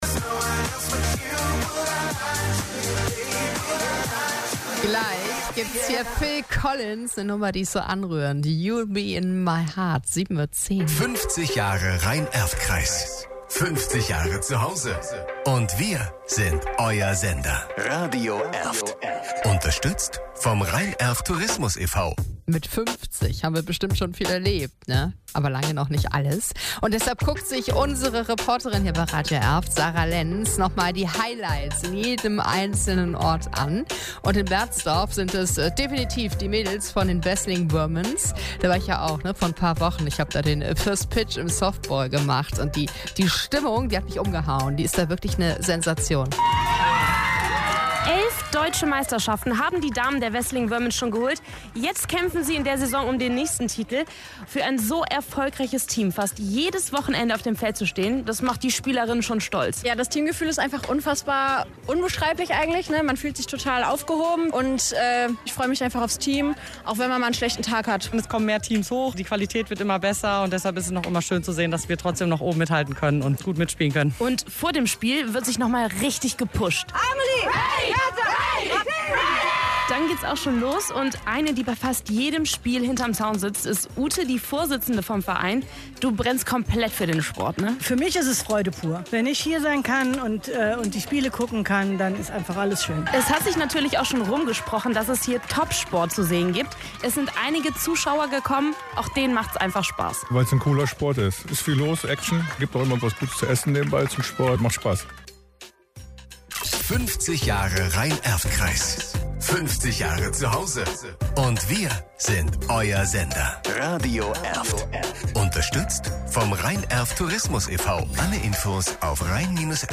Air Check Radio Erft